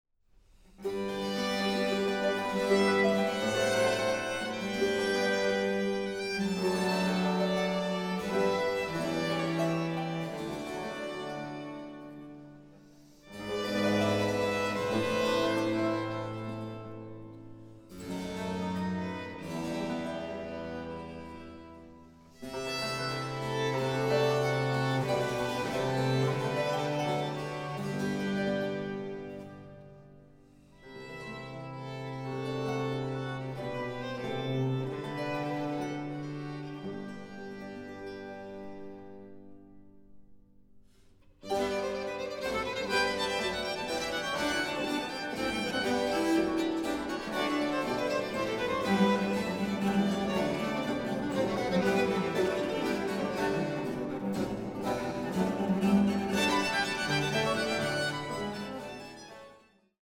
Baroque works on the theme of love in human and divine form
Soprano
The finely balanced ensemble and the agile, expressive voice